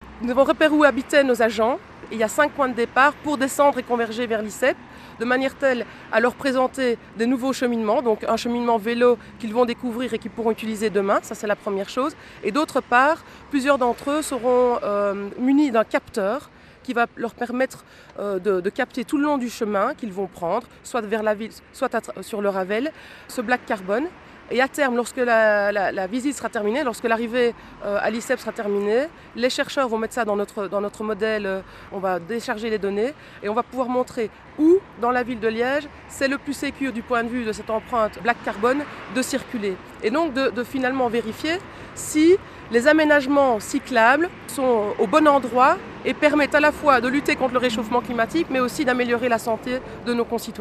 Reportages produits par la RTBF-Vivacité :
reportage 2